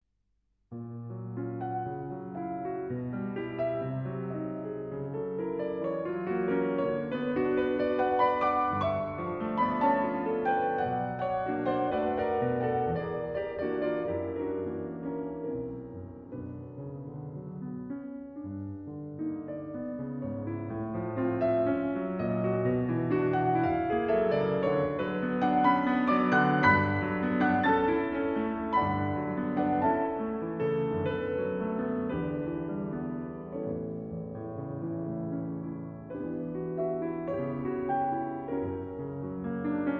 Bagatelles, Op. 147 - No. 1 In A Minor: Andante con moto